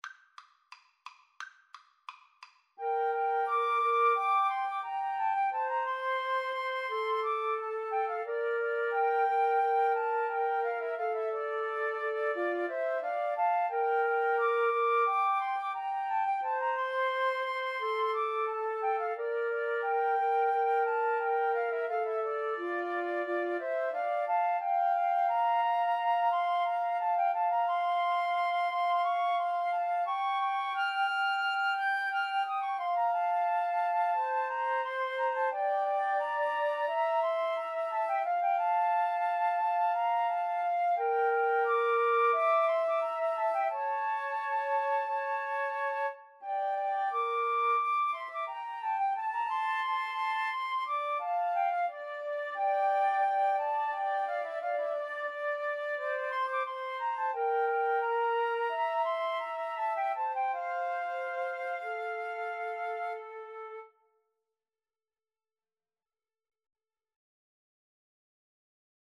Play (or use space bar on your keyboard) Pause Music Playalong - Player 1 Accompaniment Playalong - Player 3 Accompaniment reset tempo print settings full screen
F major (Sounding Pitch) (View more F major Music for Flute Trio )
Molto allegro =176